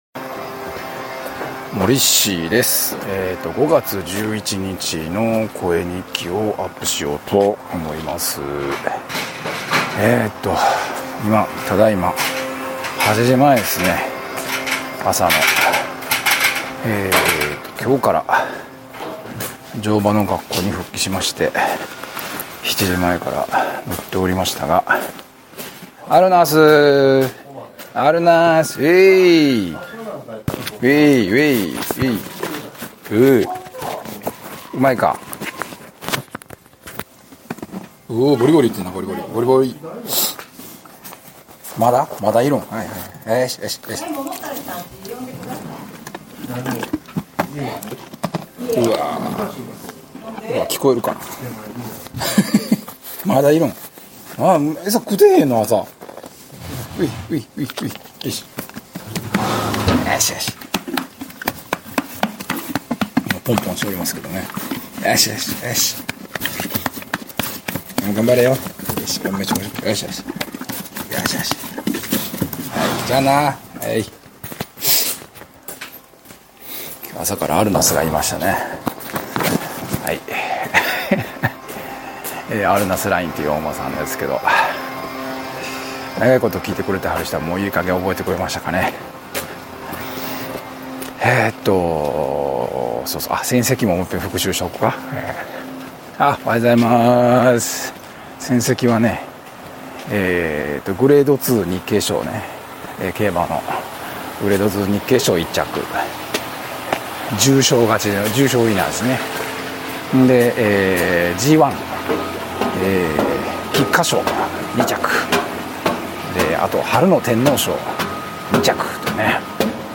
のんびりゆるゆるお馬さん#声日記